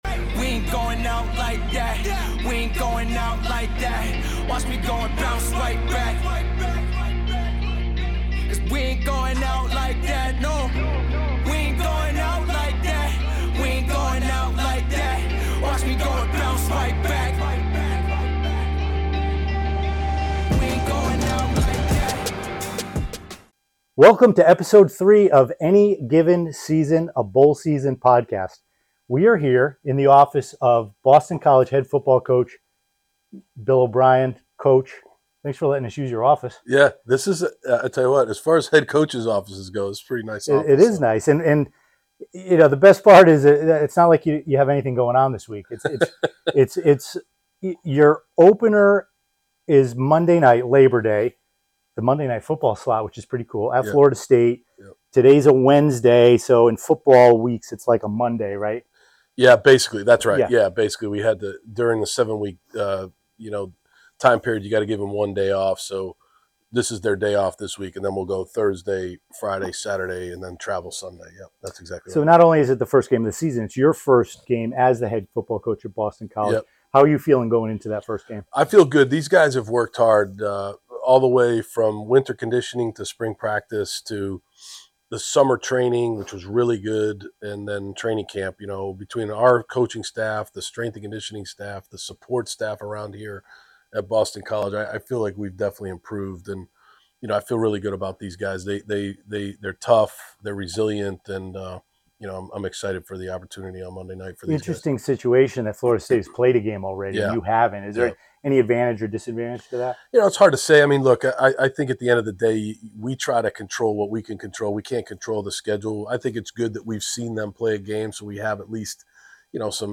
September 05, 2024 Join us for an exclusive interview with Boston College's Head Football Coach, Bill O'Brien, as he shares his journey through the world of college football and his vision for the Eagles' future. In this episode, Coach O'Brien discusses his decision to coach at Boston College, reflecting on his coaching history with highlights from his time with the Patriots, working with Tom Brady, and his experiences under legendary coaches Bill Belichick and Nick Saban.